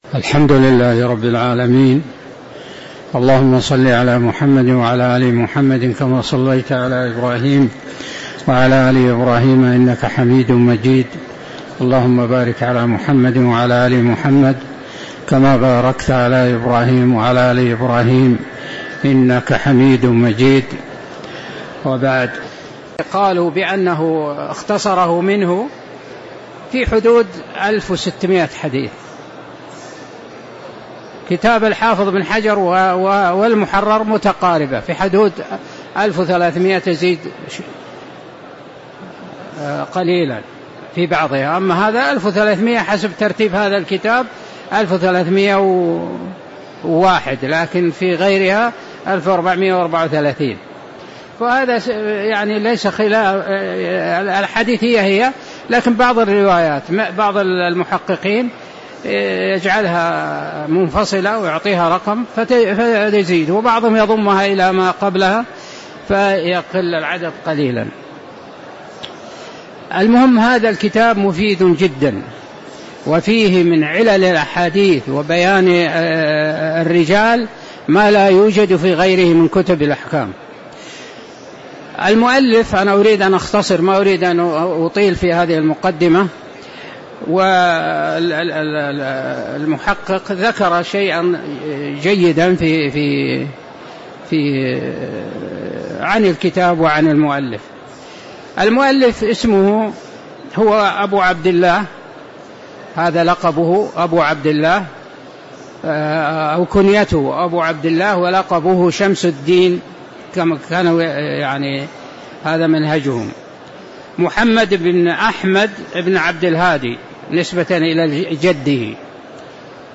تاريخ النشر ٢٦ صفر ١٤٤٥ هـ المكان: المسجد النبوي الشيخ